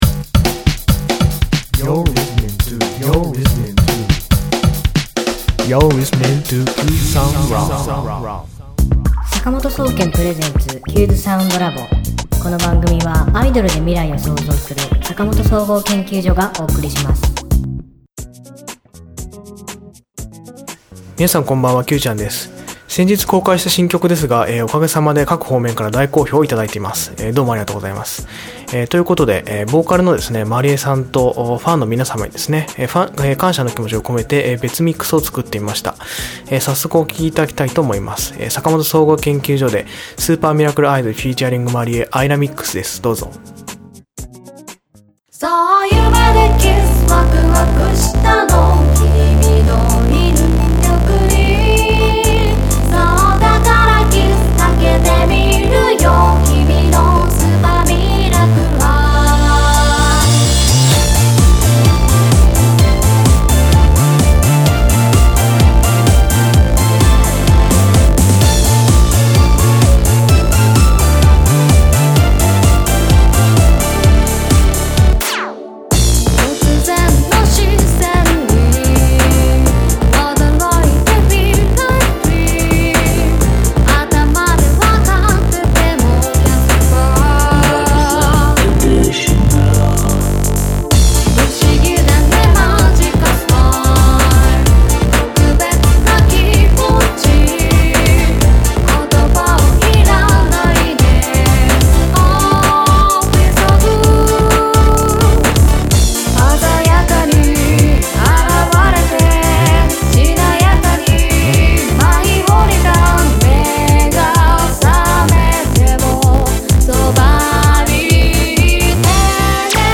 今週の挿入歌
歌・コーラス